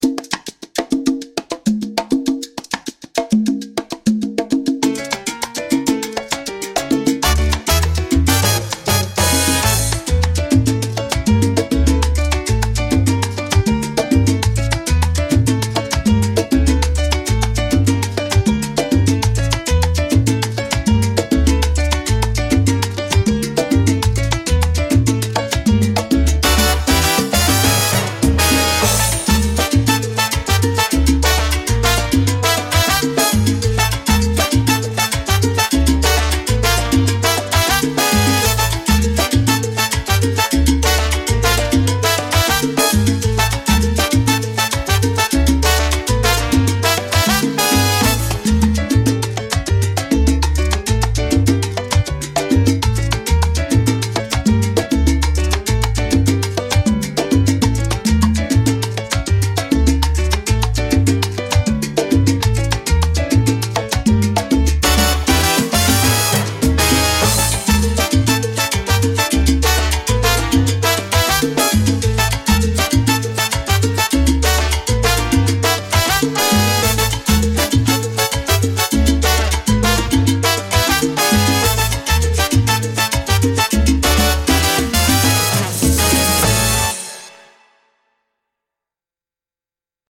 latin-inspired dance music with congas, timbales and bright trumpet riffs